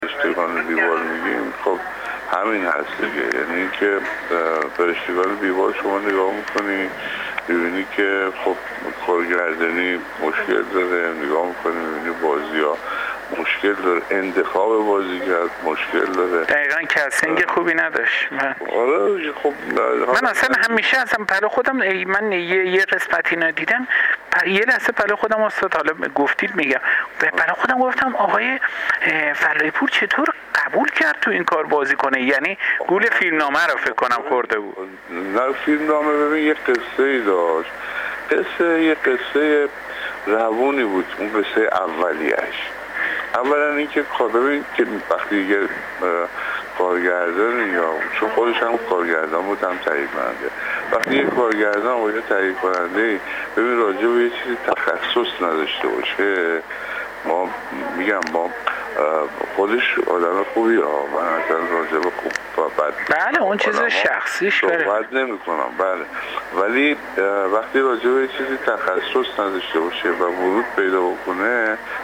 پرویز فلاحی‌پور در گفت‌وگو با ایکنا: